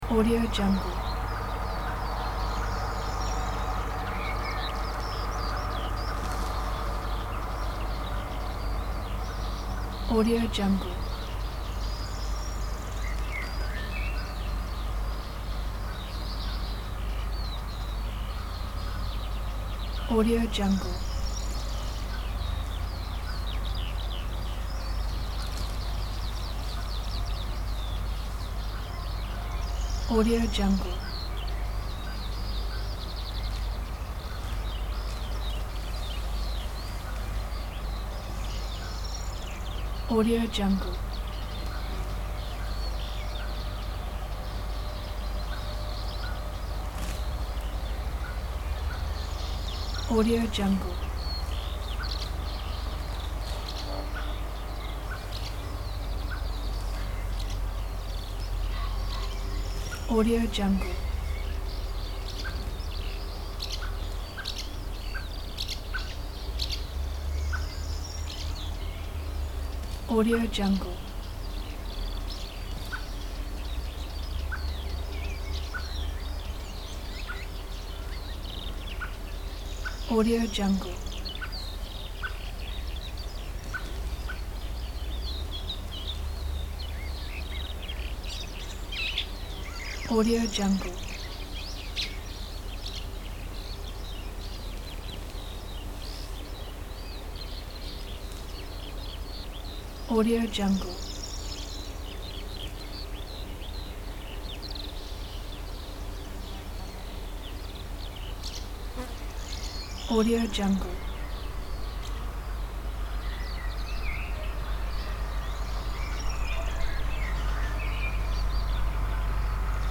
دانلود افکت صدای محیط مزرعه در تابستان
افکت صدای محیط مزرعه در تابستان یک گزینه عالی برای هر پروژه ای است که به صداهای طبیعت و جنبه های دیگر مانند محیط، مزرعه و مزرعه نیاز دارد.
Sample rate 16-Bit Stereo, 44.1 kHz
Looped Yes